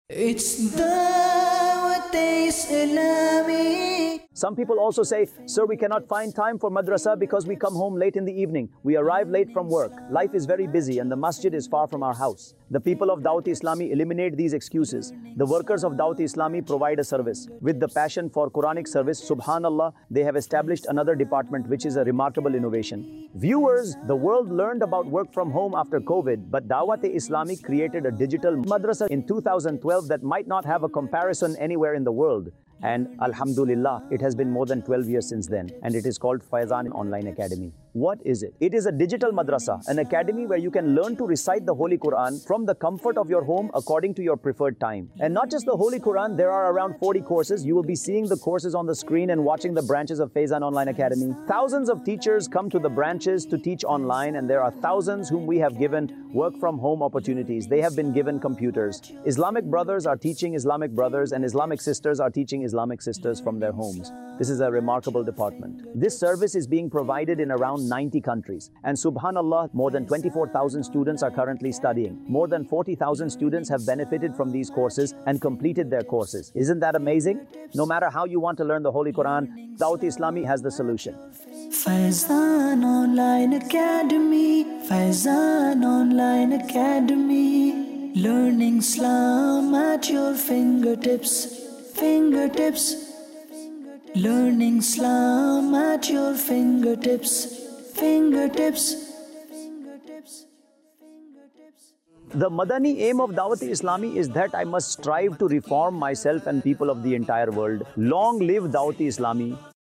Faizan Online Academy | Department of Dawateislami | Documentary 2025 | AI Generated Audio Mar 22, 2025 MP3 MP4 MP3 Share فیضان آن لائن اکیڈمی | شعبہِ دعوت اسلامی | ڈاکیومینٹری 2025 | اے آئی جنریٹڈ آڈیو